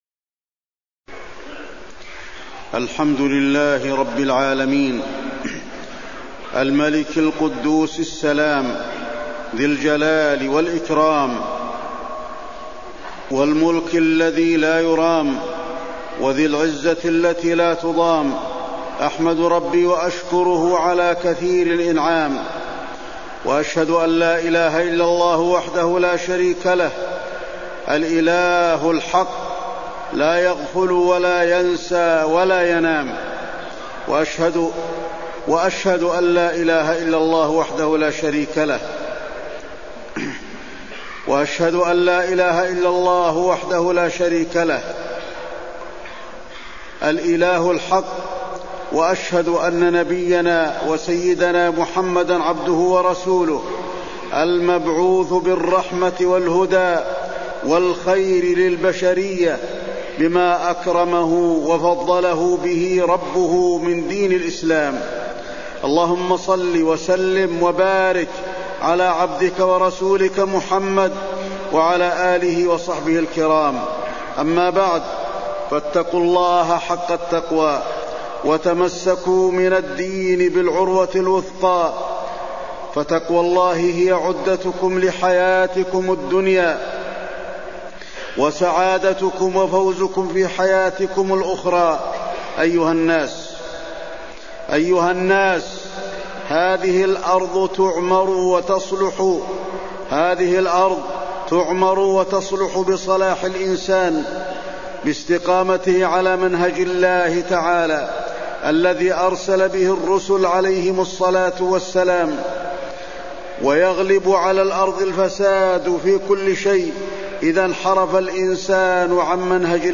تاريخ النشر ٨ ذو الحجة ١٤٢٧ هـ المكان: المسجد النبوي الشيخ: فضيلة الشيخ د. علي بن عبدالرحمن الحذيفي فضيلة الشيخ د. علي بن عبدالرحمن الحذيفي يوم عرفة وفضله